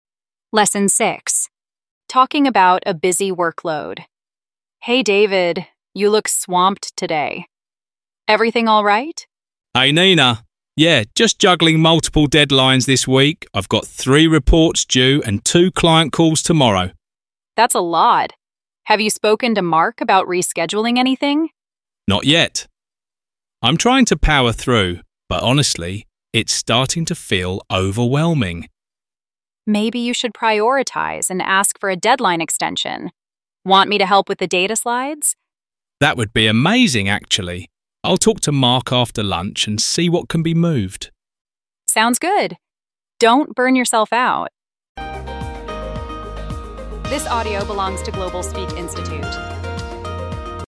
• Hình thức: Sách + Audio luyện phản xạ
Giọng tự nhiên Từ vựng & câu ví dụ